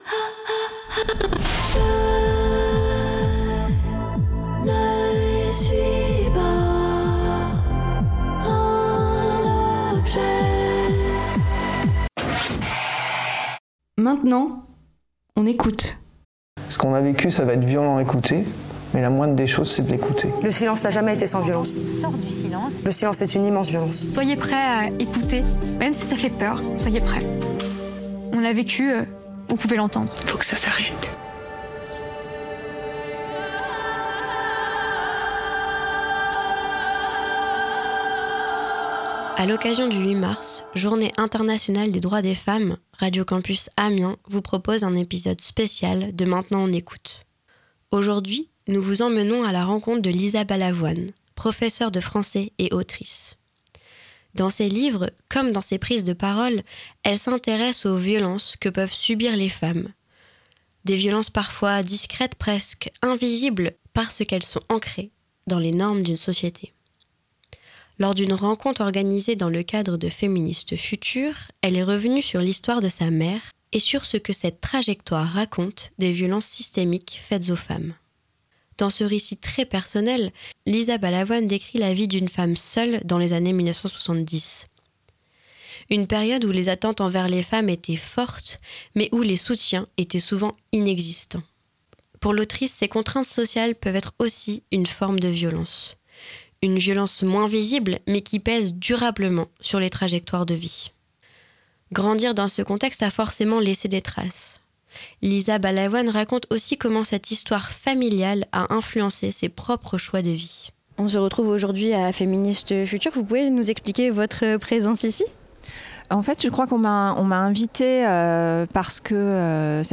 Dans ses livres comme dans ses prises de parole, elle s’intéresse aux violences que peuvent subir les femmes, parfois de manière discrète, presque invisible, parce qu’elles sont ancrées dans la société. Lors d’une rencontre organisée pendant le festival Féministes Futures, elle est revenue sur l’histoire de sa mère et sur ce que cette trajectoire raconte des violences systémiques faites aux femmes.